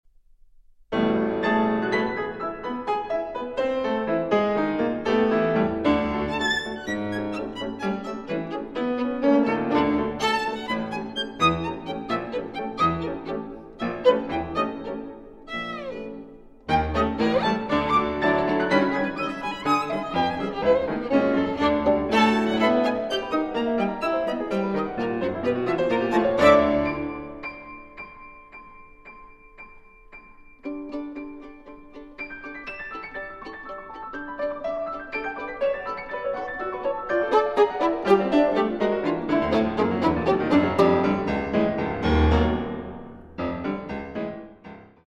Sonata for Violin and Piano (1963) (22:57)
Allegro (8:43)